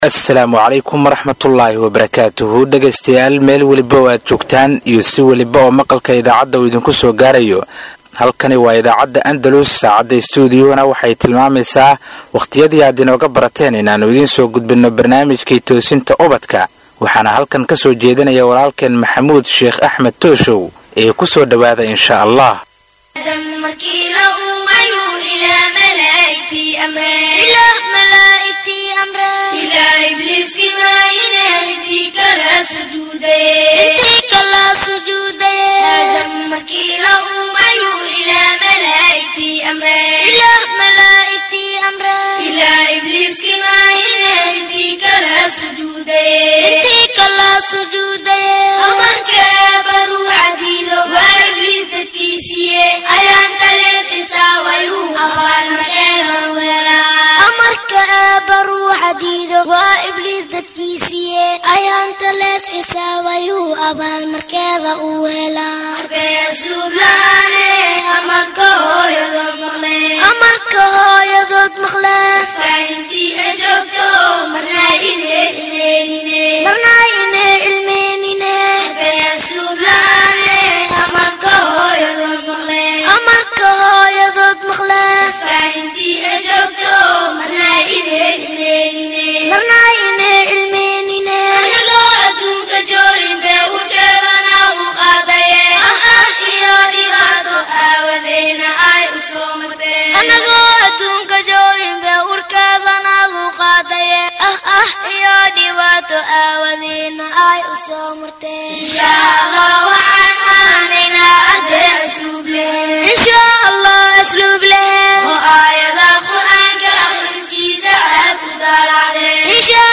Halkan waxad ka dhagaysan kartaa barnaamijka todobaadlaha ah ee Toosinta Ubadka kaasi oo ka baxa idaacadda Andalus, barnaamijkan oo ah barnaamij ay caruurtu aad u xiisayso wuxuu ka koobanyahay dhowr xubnood oo kala ah wicitaanka iyo bandhiga caruurta, jawaabta Jimcaha, Iftiiminta qalbiga iyo xubinta su aasha toddobaadka.